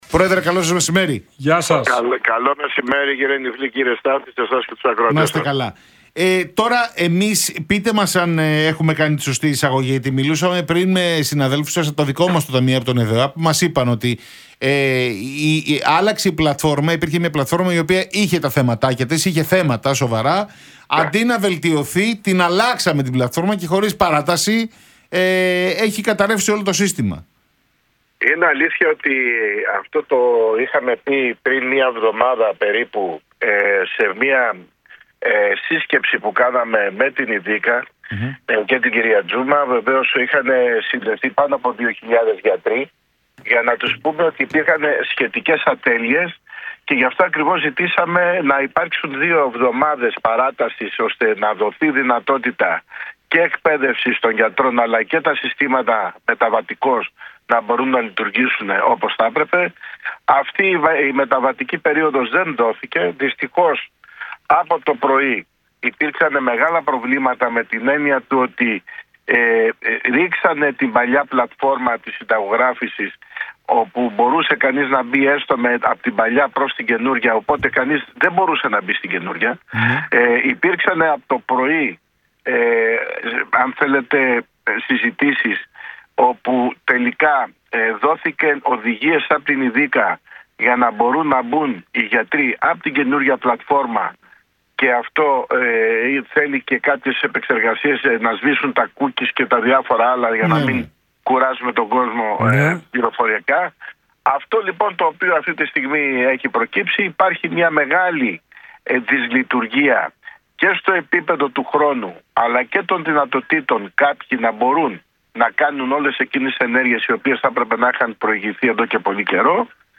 Ο πρόεδρος του Ιατρικού Συλλόγου Αθηνών, Γιώργος Πατούλης μιλώντας στον Realfm 97,8